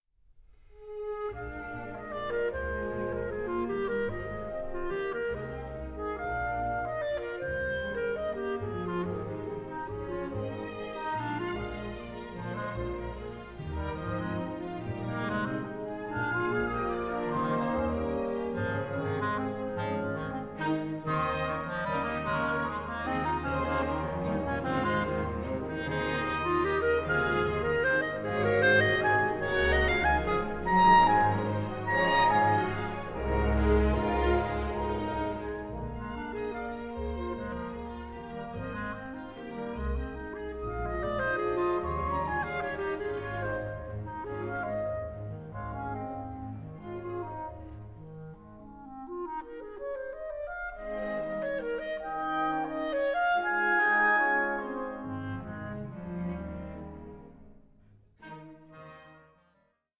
Belgian clarinettist